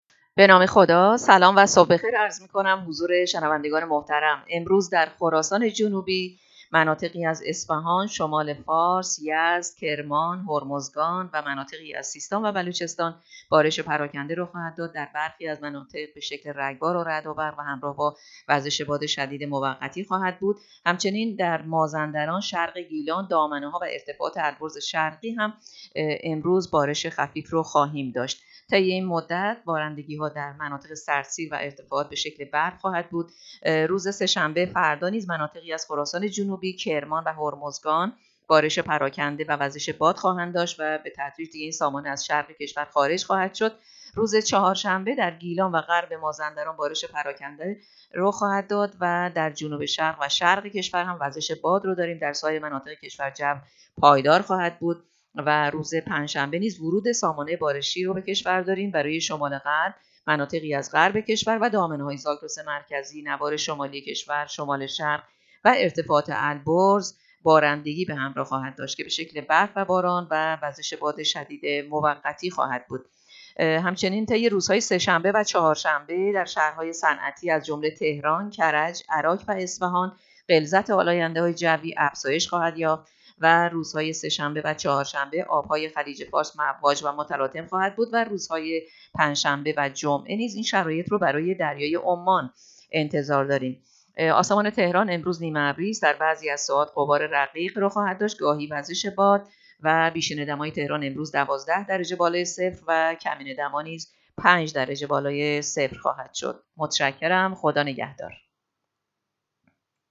گزارش رادیو اینترنتی پایگاه‌ خبری از آخرین وضعیت آب‌وهوای ۲۴ دی؛